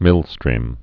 (mĭlstrēm)